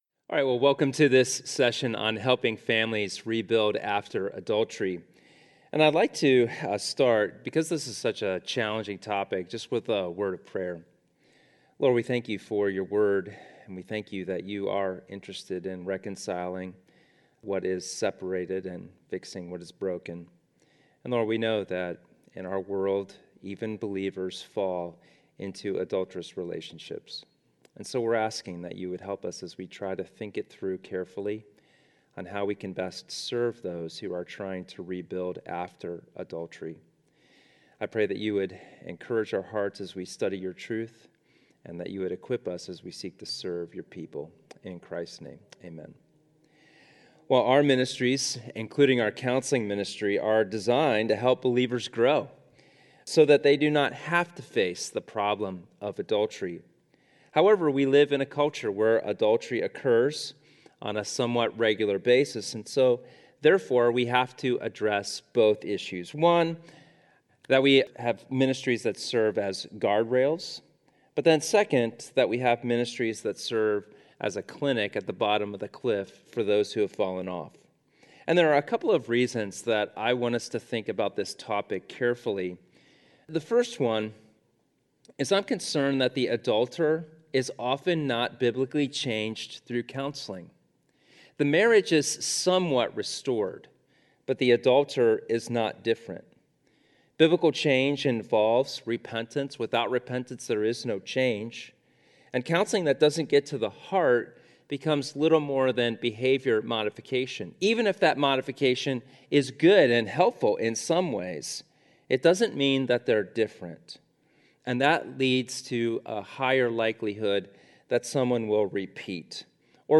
This is a session from a Biblical Counseling Training Conference hosted by Faith Church in Lafayette, Indiana.